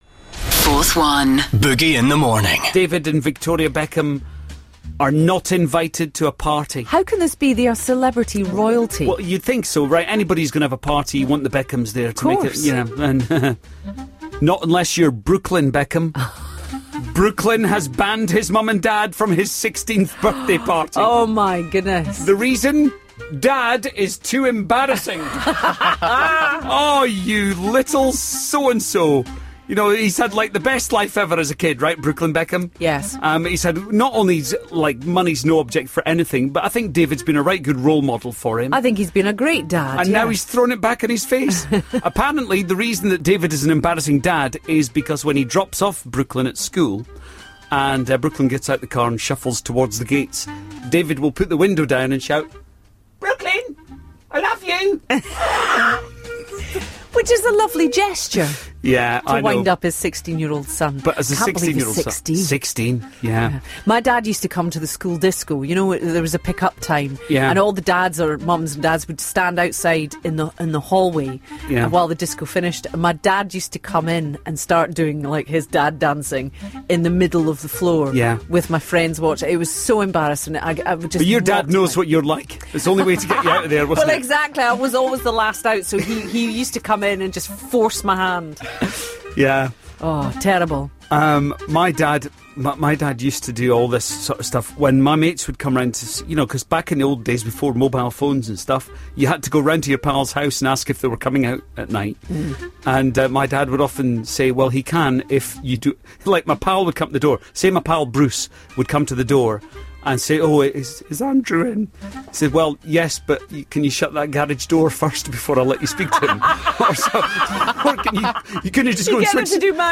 Brooklyn Beckham hasn't invited his Dad to his birthday party because he is embarrassing. You called us about this times where you Dad really embarrassed you as a kid. Listen to hear how one Dad embarrassed his son with pink pajamas.